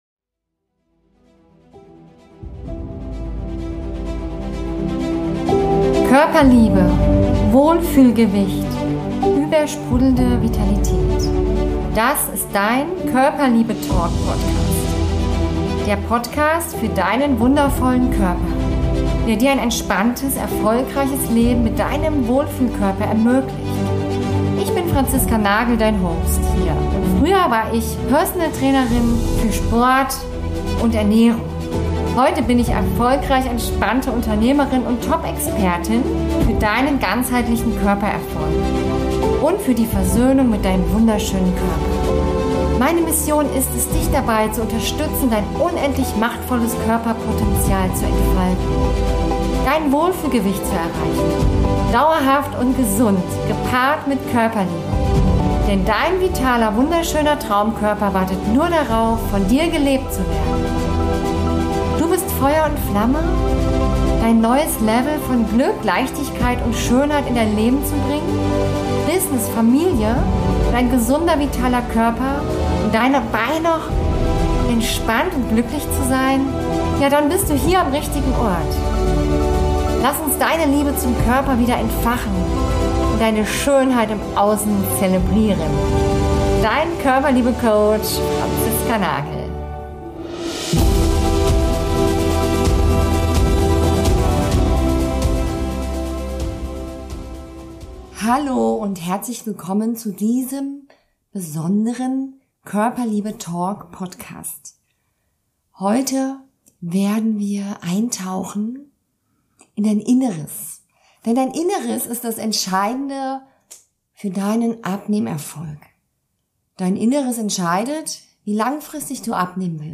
Und deswegen habe ich heute eine Meditation für dich vorbereitet, mit der du dir selbst begegnen wirst.